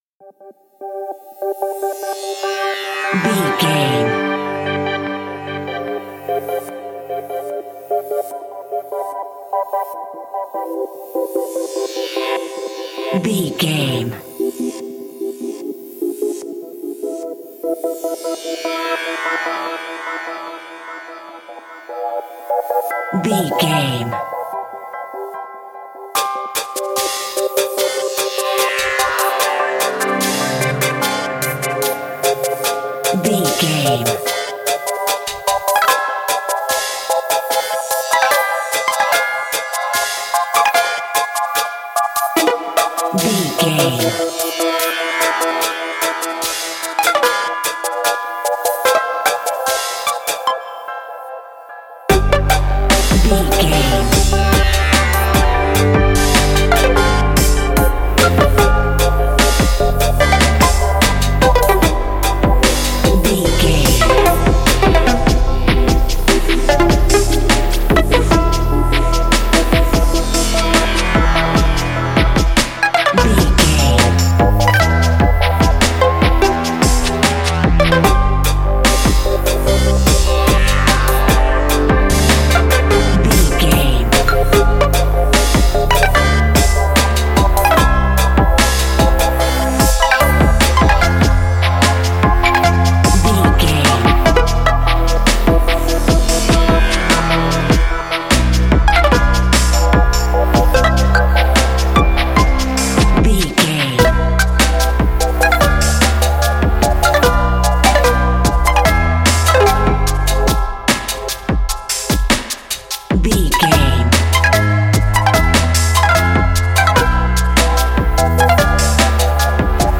A chaotic electro track with dissonant synths
Its unpredictable pulse drives tension and immersion.
Ionian/Major
Slow
electronic
eerie
industrial
dissonant
ambient